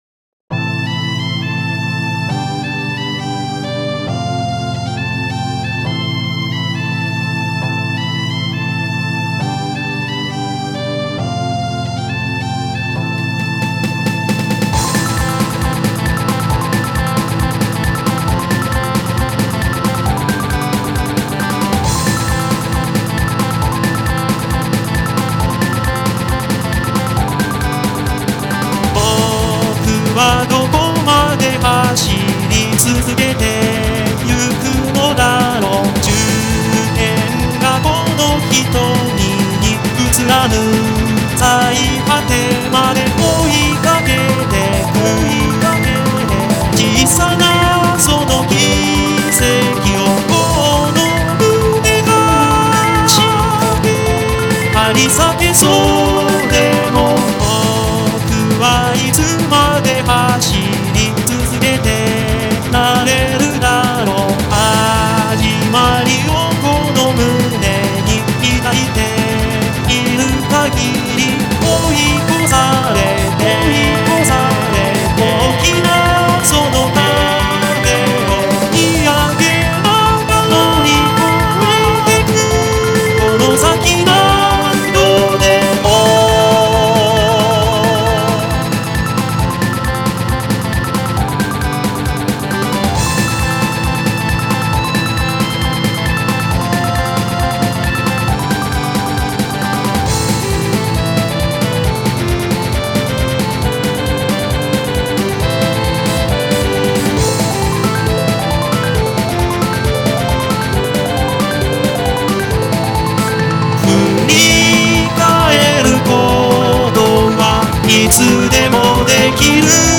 歌もの（ＶＯＣＡＬＯＩＤを使用したもの）
テンポ速いの。